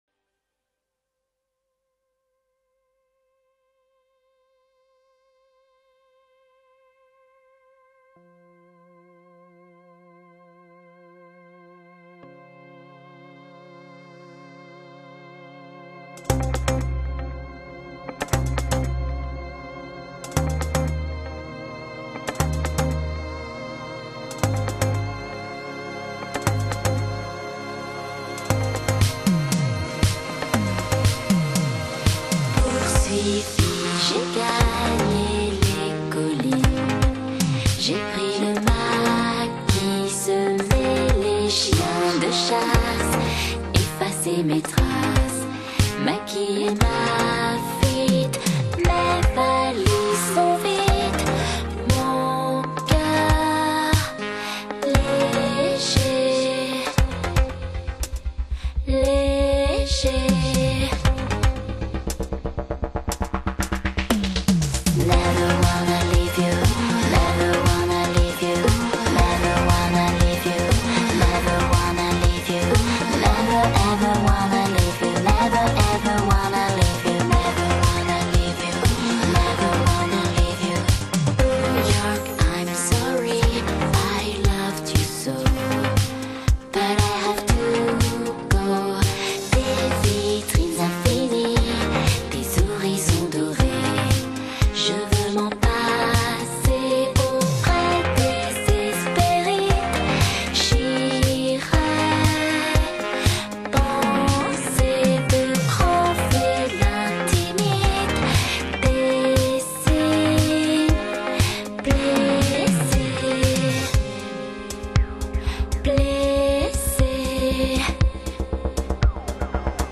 Pop, Electronic